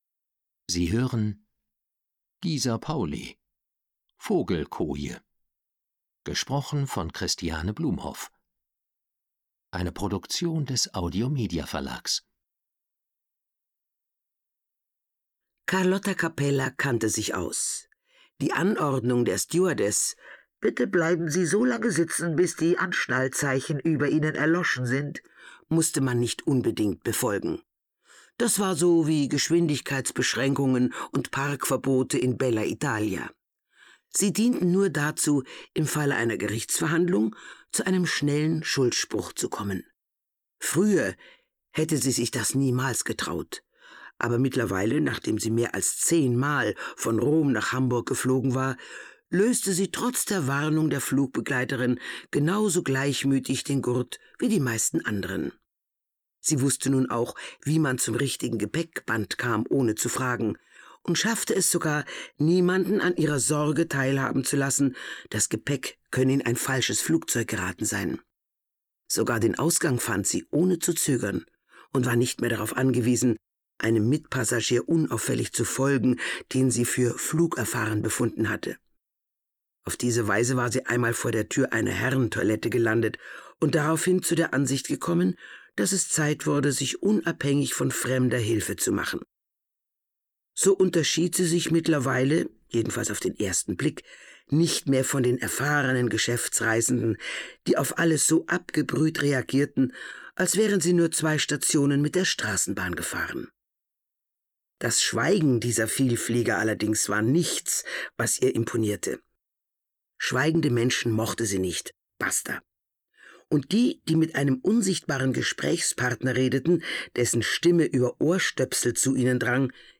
Schlagworte Autorennen • Cozy Mystery / Cosy Mystery / Provinzkrimi • Deutsch • Deutschland • Ermittlerin • Hörbuch; Krimis/Thriller-Lesung • Italien • Kriminalromane & Mystery: Cosy Mystery • Kriminalromane & Mystery: Cosy Mystery • Krimis/Thriller; Humor • Leichenwagen • Mamma Carlotta • Nordseeküste und -inseln • Rätsel • Sarg • Schwiegermutter • Sylt • Sylt; Krimis/Thriller • Unglück